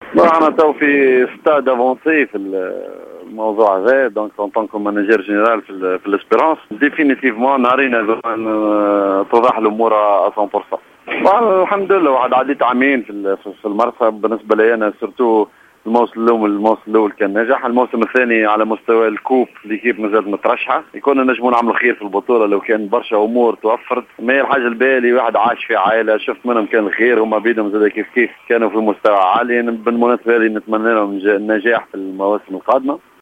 أكد المدرب المنذر الكبير في تصريح لجوهرة أف أم أن إتفاقا رسميا قد حصل بينه و بين هيئة الترجي الرياضي التونسي للإنضمام إلى الإدارة الفنية للفريق من خلال تعيينه في خطة مدير فني للفريق مشيرا أن المفاوضات بلغت شوطا هاما و ينتظر الإعلان الرسمي خلال الأسبوع القادم.
المنذر الكبير : مدرب